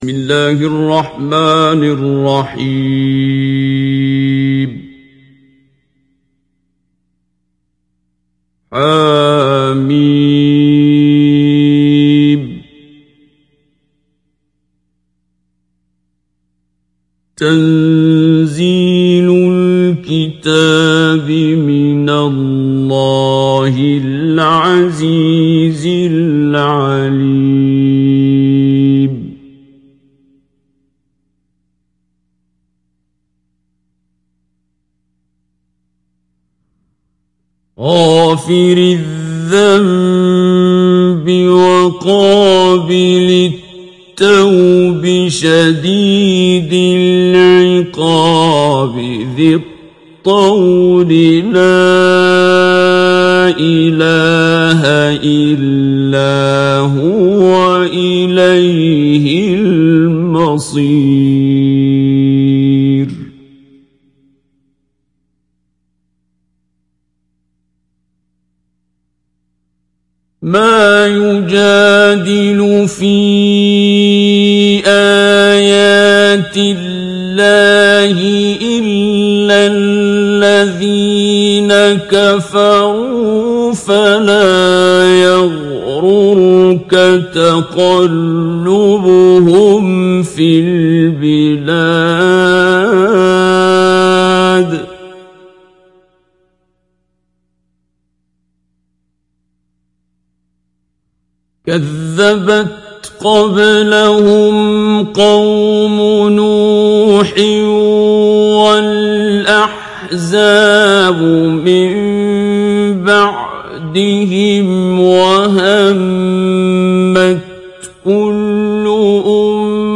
Қуръони карим тиловати, Қорилар.